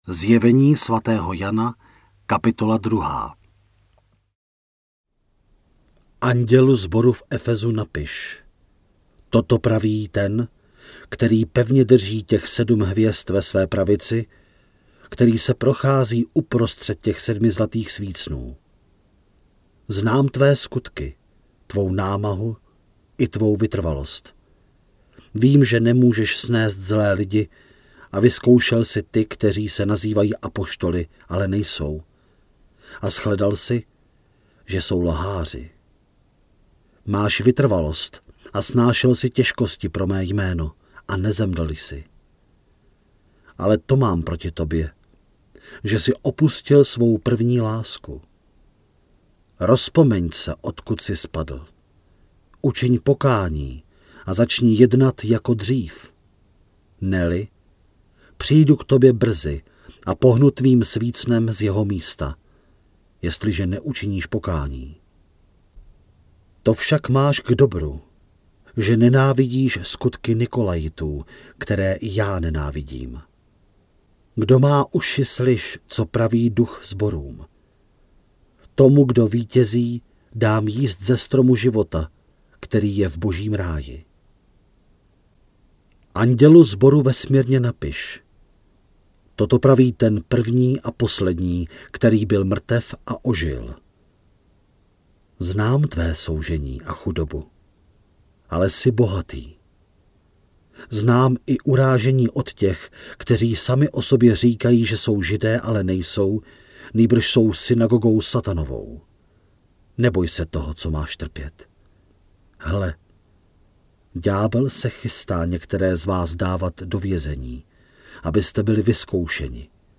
Načtená kniha je rozdělená na 22 částí, které odpovídají dělení kapitol.
Stahujte celé zde (75MB):  ZJEVENÍ SVATÉHO JANA – audiokniha, Studio Vox 2018